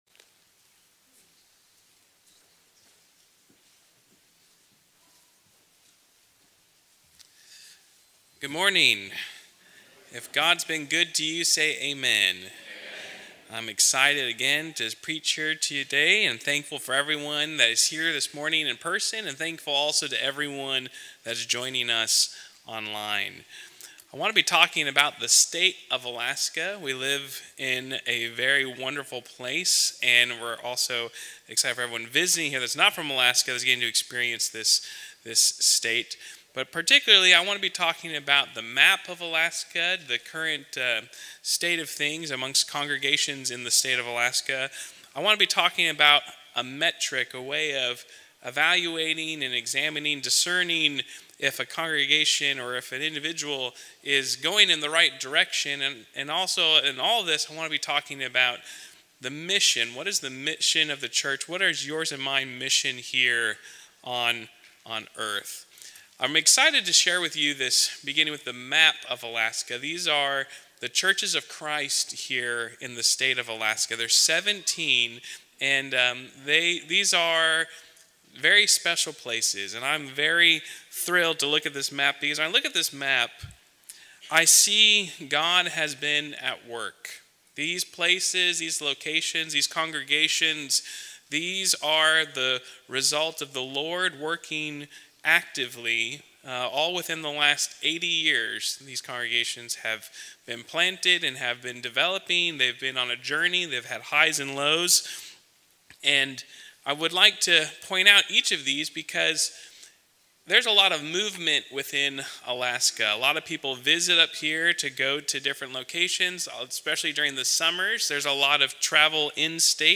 Sermons - 2025